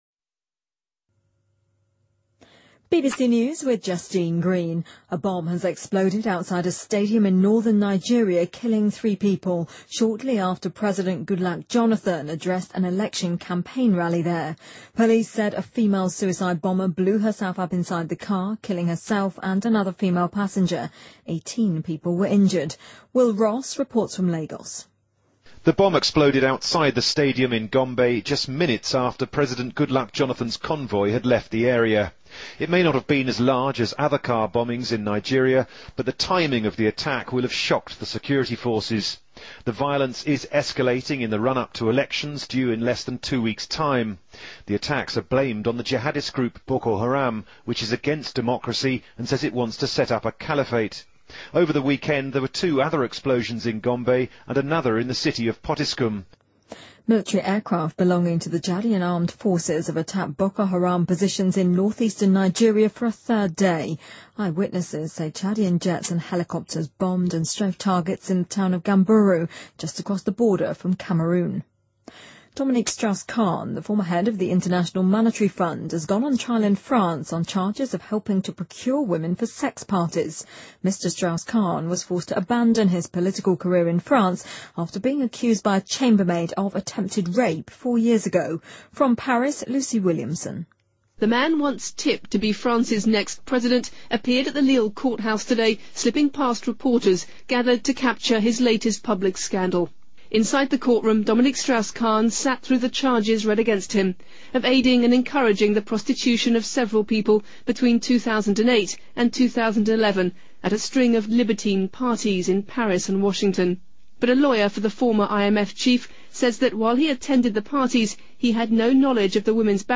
BBC news